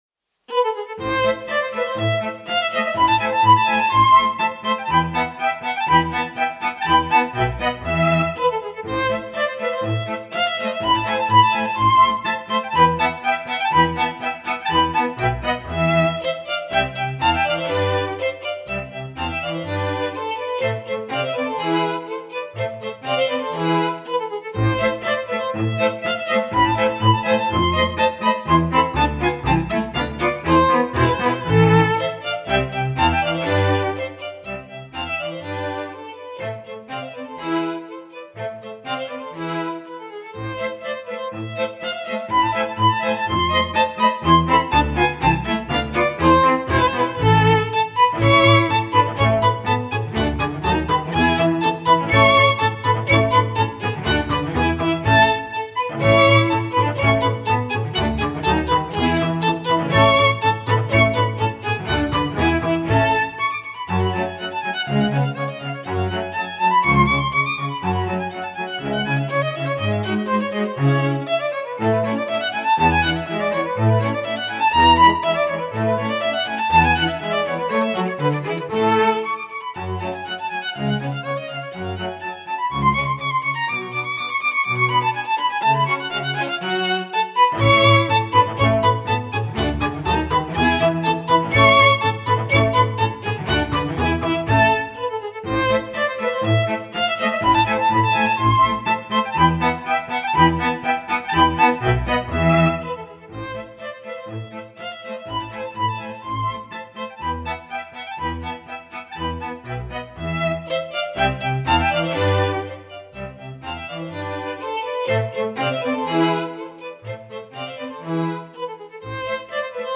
Chris Hein Solo Strings Complete 是一款包含了 4 种独奏弦乐器的虚拟乐器库，它使用了 NI 的 Kontakt 引擎，可以在各种音乐风格中提供最逼真和最富有表现力的弦乐声音。
- 4 种小提琴：Solo Violin、Italian Violin、French Violin 和 Original 1826
- 3 种中提琴：Solo Viola、Canadian Viola 和 French Viola
- 4 种大提琴：Romantic Cello、Modern Cello、British Cello 和 French Cello
- 3 种低音提琴：Russian Bass、German Bass 和 Italian Bass
Chris-Hein-Solo-Strings-Complete.mp3